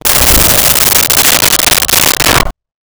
Creature Snarl 02
Creature Snarl 02.wav